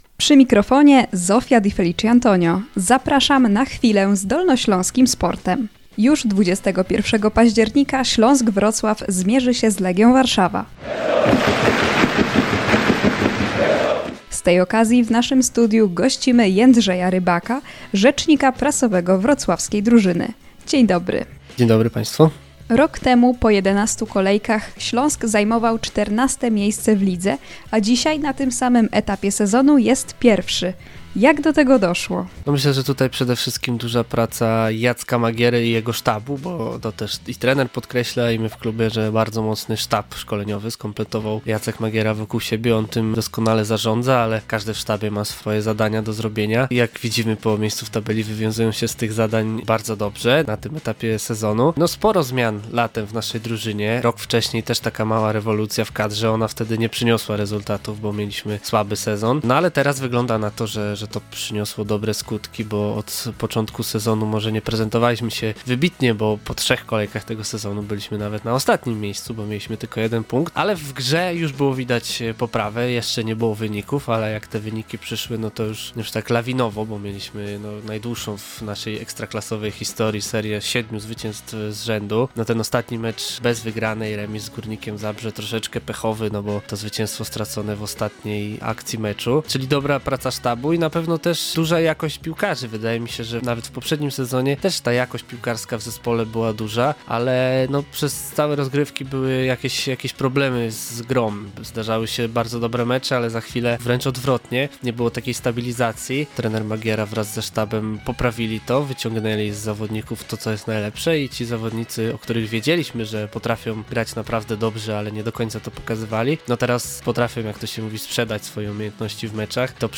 cz.1-rozmowy.mp3